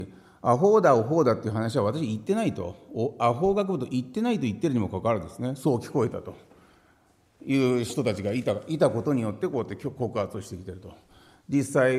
資料3　井桁議員が行った計4回の懲罰に対する弁明　音声⑥　（音声・音楽：63KB）